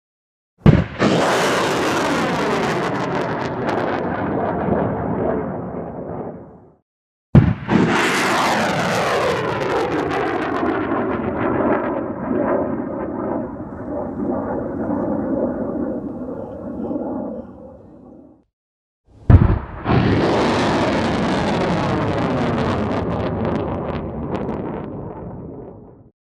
Âm thanh Phóng Tên Lửa trên tàu
Đánh nhau, vũ khí 460 lượt xem 04/03/2026
Hiệu ứng âm thanh phóng tên lửa trên tàu tái hiện chân thực sức mạnh của công nghệ quân sự hiện đại với tiếng rít xé gió từ động cơ đẩy và tiếng nổ đẩy tầng khởi động cực mạnh. Đây là dải âm thanh mang tính quy mô, mô phỏng quá trình tên lửa rời khỏi ống phóng thẳng đứng (VLS) hoặc bệ phóng trên boong tàu, tạo nên một luồng âm thanh dồn dập và vang dội trên mặt biển.